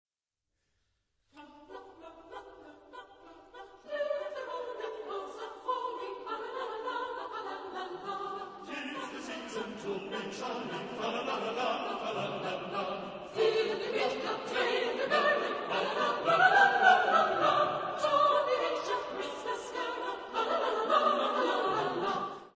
Genre-Style-Form: Secular
Type of Choir: SATB  (4 mixed voices )